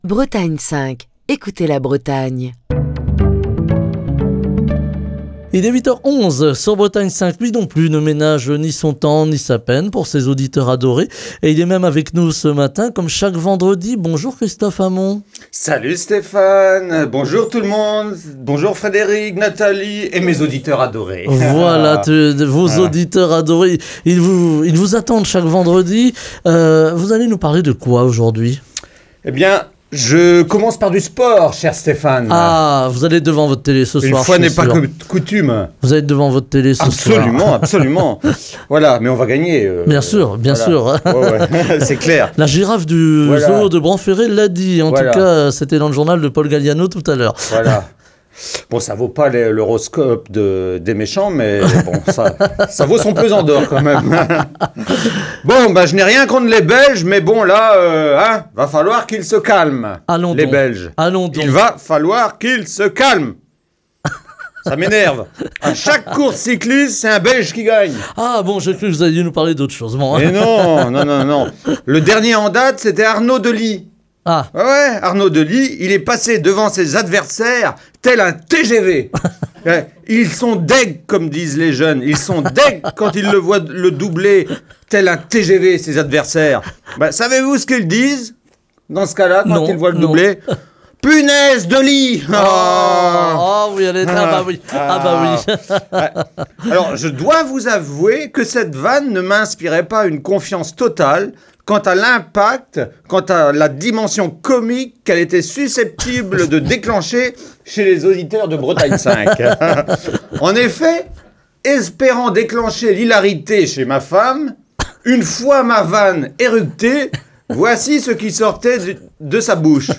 Chronique du 6 octobre 2023. Il a été beaucoup question de sport et de punaises de lit cette semaine dans l'actualité.